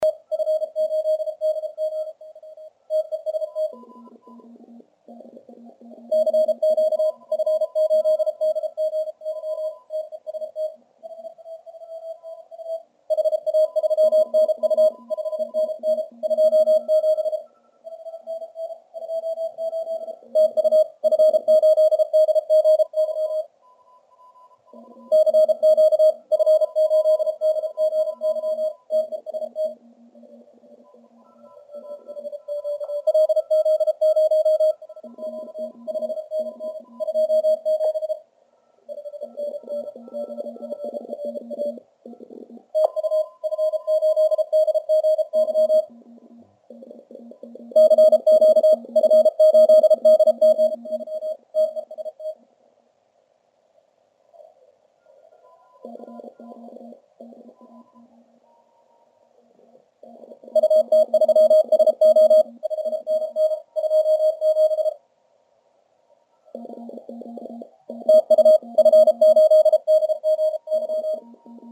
Запись велась: в CW 200Hz, в SSB 2,4kHz.
Продолжаю выкладывать записи работы приемника (tr)uSDX-a. Запись делалась во время проведения IARU HF Championship.